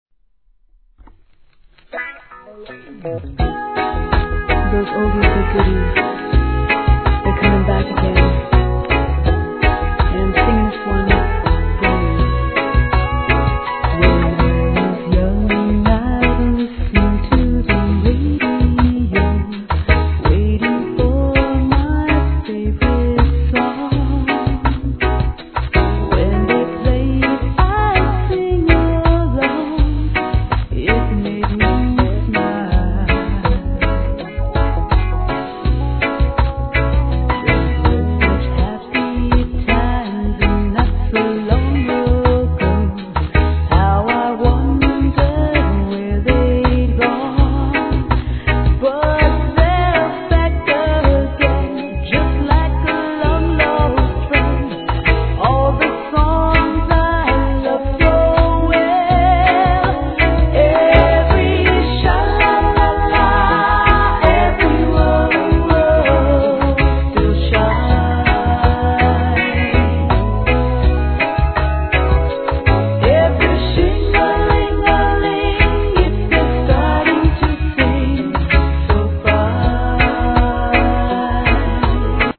REGGAE
名曲カヴァー